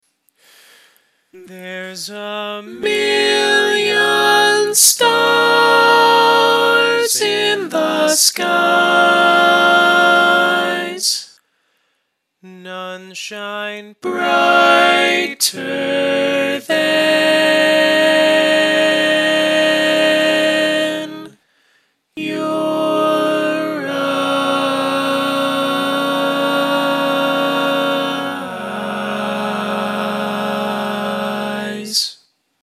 Key written in: G♭ Major
Type: Barbershop